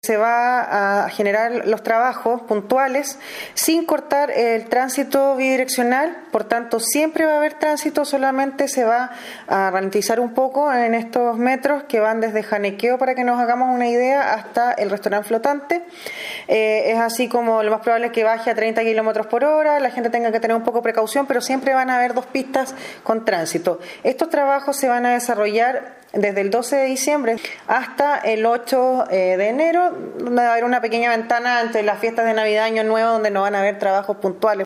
Será un tramo de 70 metros, en donde pese a la intervención, siempre habrá dos pistas habilitadas indicó la seremi, Sandra Ili,